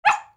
Puppy.wav